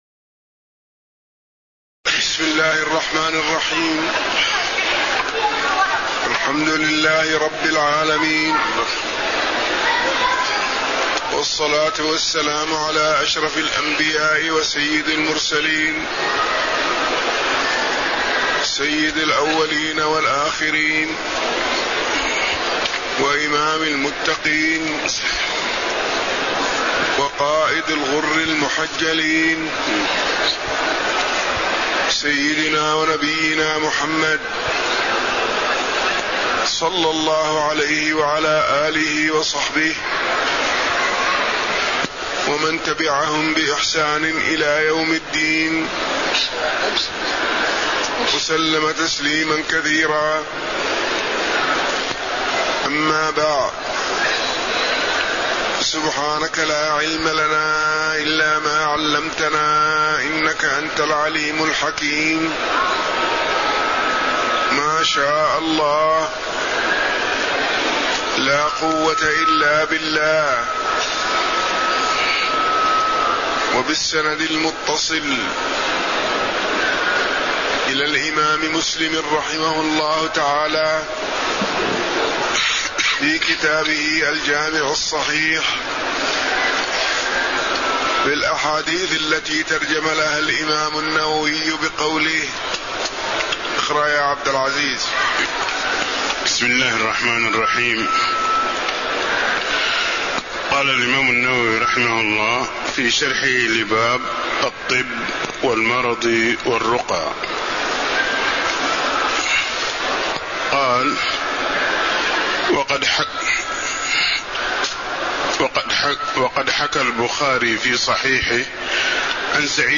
تاريخ النشر ٢٢ محرم ١٤٣٧ هـ المكان: المسجد النبوي الشيخ